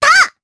Lilia-Vox_Attack2_jp.wav